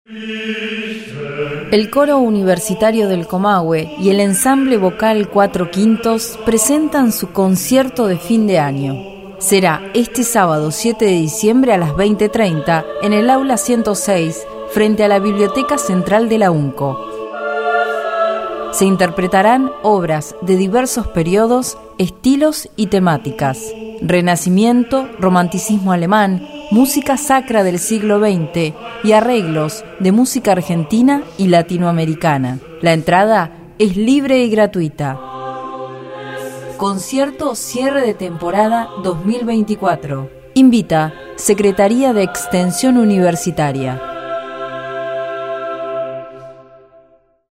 El Coro Universitario del Comahue junto al Ensamble Vocal Cuatro Quintos cerrarán la exitosa temporada 2024 con obras de diversos periodos, estilos y temáticas: renacimiento, romanticismo alemán, música sacra del siglo XX y arreglos de música argentina y latinoamericana.
Concierto-de-fin-de-ano-Coro.mp3